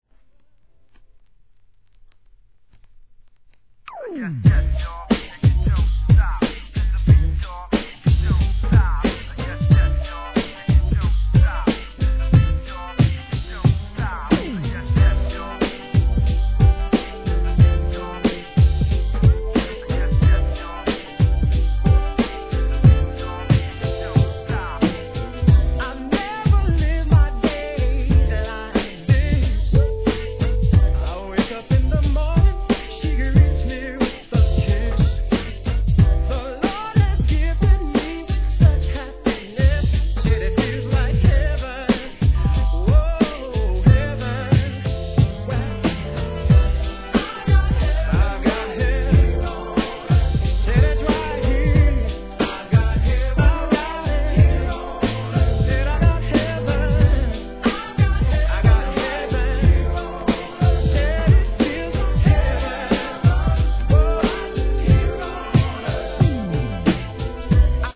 1. HIP HOP/R&B
N.Y.の硬派ヴォーカル・グループの大ヒット作が2枚組みのREMIXESで登場!!